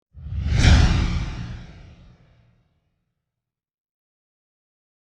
Pass Swoosh Sound Effect Free Download
Pass Swoosh